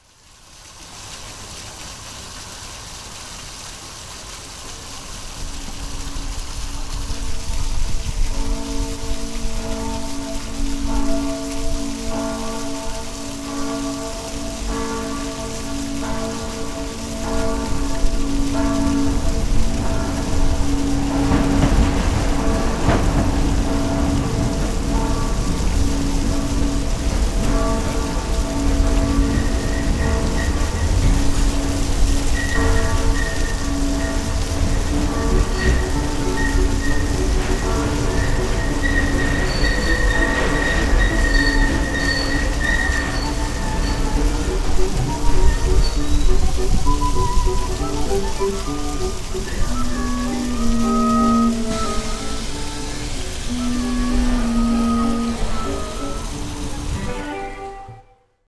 Fountain field recording in Budapest reimagined